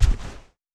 Foley Sports / Soccer / Tackle Distant.wav
Tackle Distant.wav